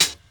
006_Hi-Hat 01 - No Man's Joint.wav